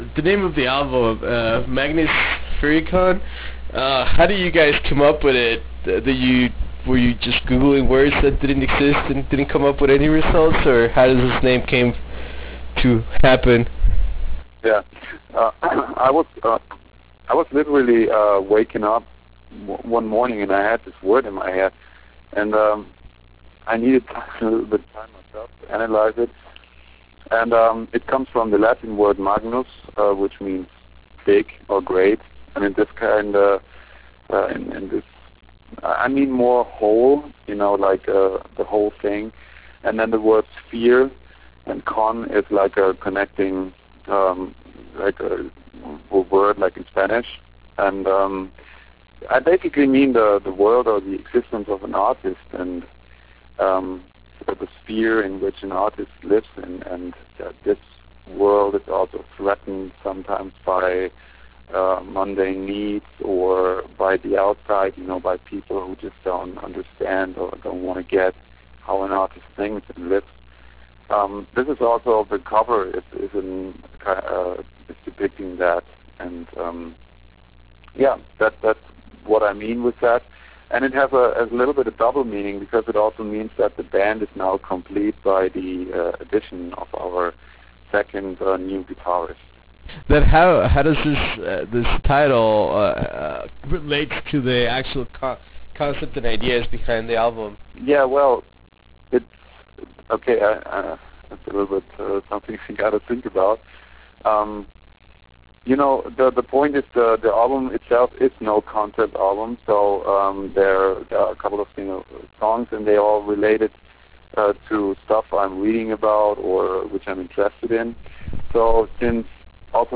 Interview with Sons of Seasons - Oliver Palotai
Interview with Oliver Palotai - Sons of Seasons.wav